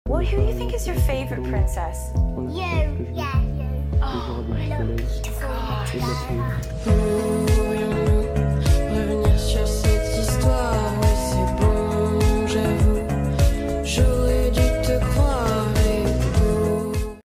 cutest interview ever | cc: sound effects free download